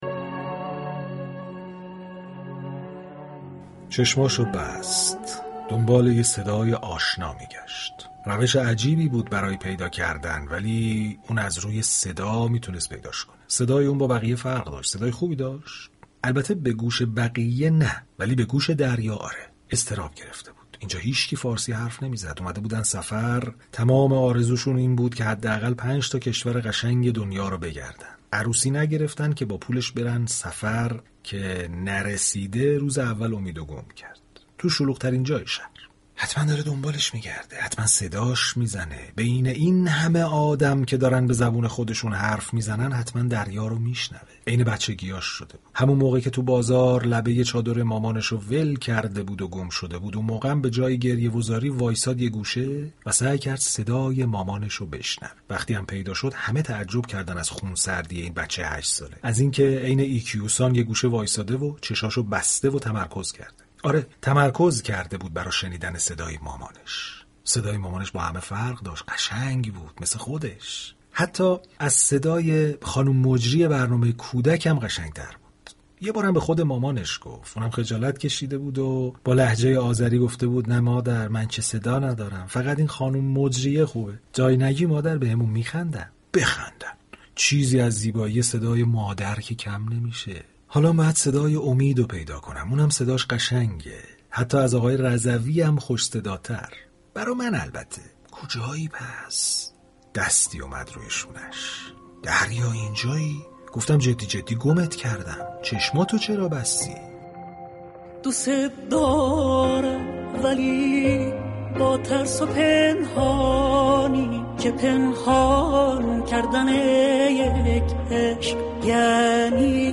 روایت داستان های شنیدنی از كتاب های جالب در كنار پخش دیالوگ های فیلم ها این برنامه را جالب كرده است.